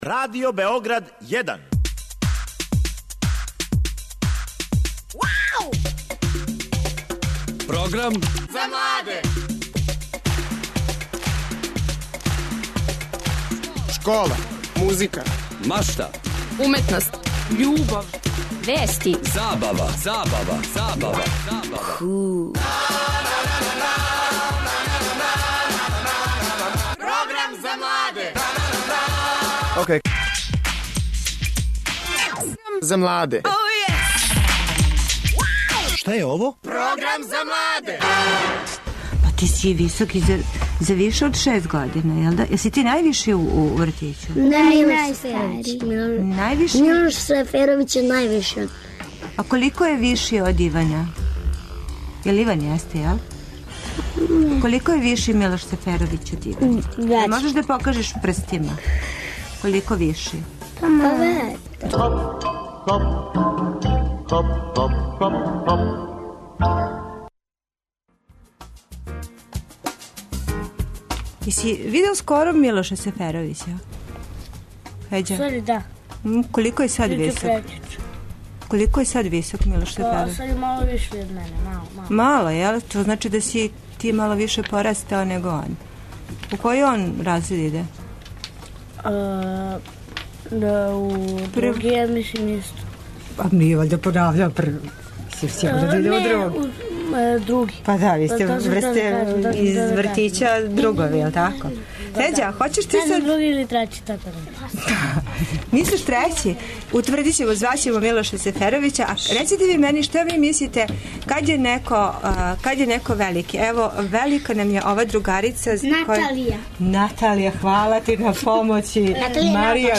Учитељица доводи (скоро) сву децу из продуженог боравка.
Наравно, не сви у исто време јер, мали је студио...